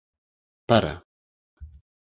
Pronúnciase como (IPA)
[pɐɾɐ]